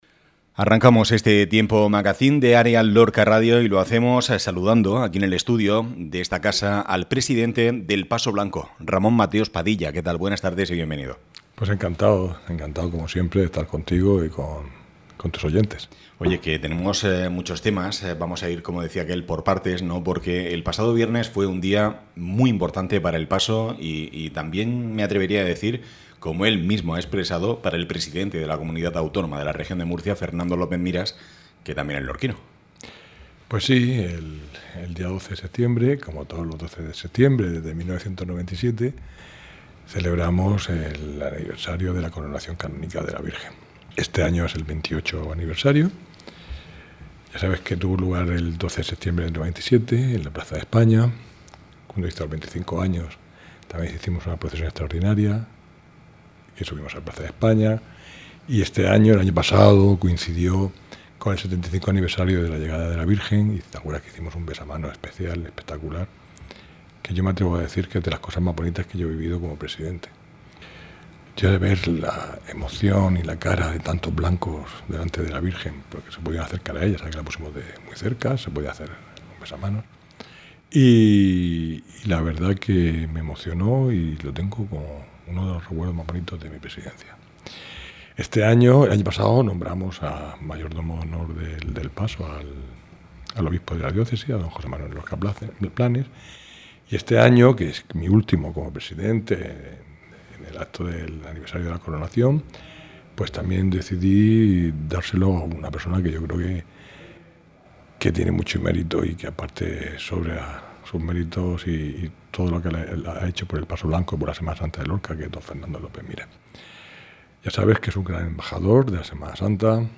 Cultura.